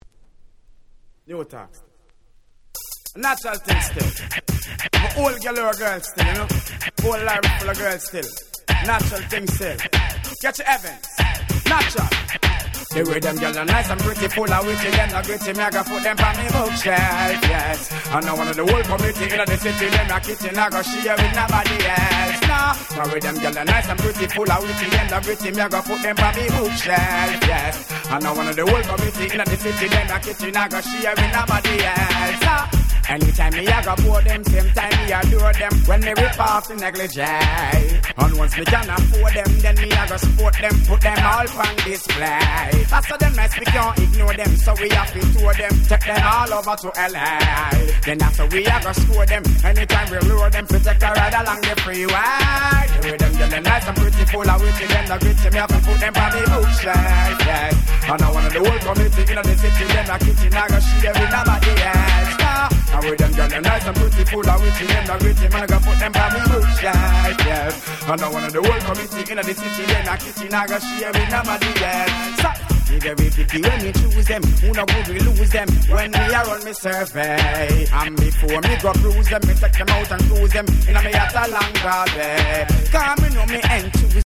98' Dancehall Reggae Super Classics !!